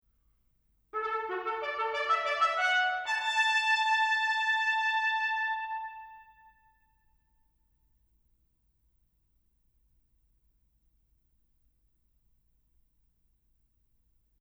Accompanied: Unaccompanied Solo